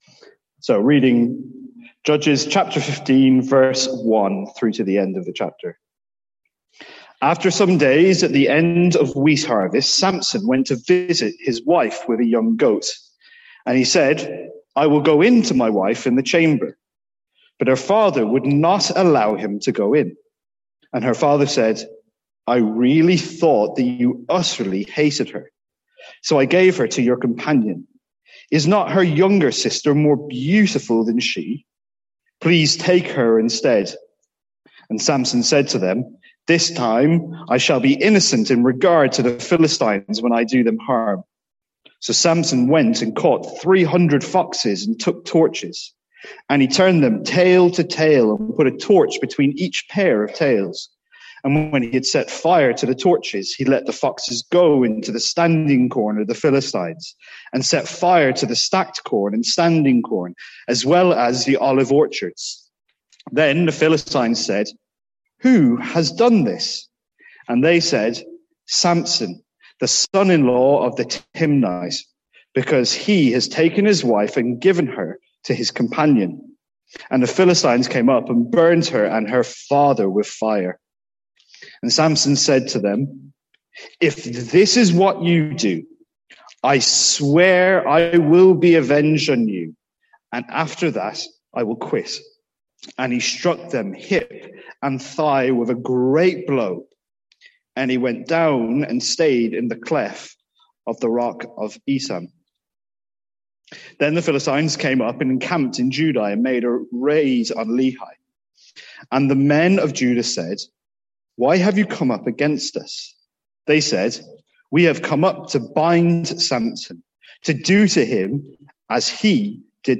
Sermons | St Andrews Free Church
From our morning series in Judges.